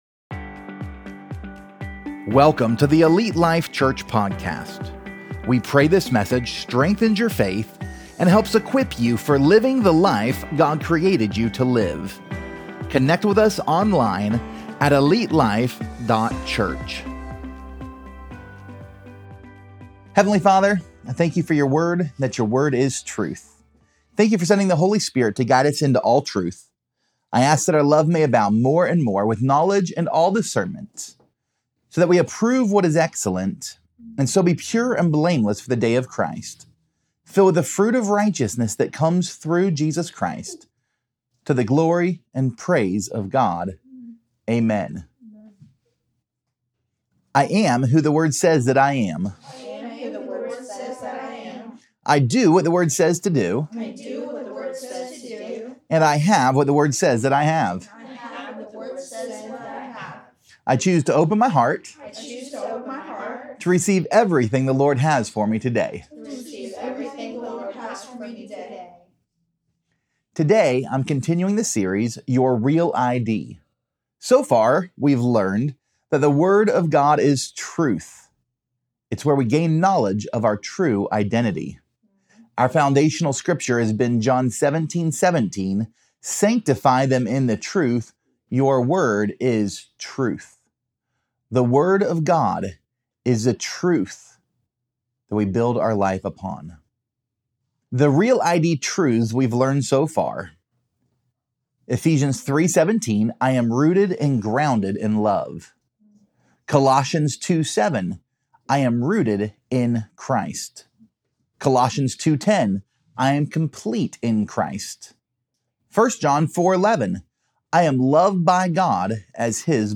Pt 09: The Verdict | Your REAL ID Sermon Series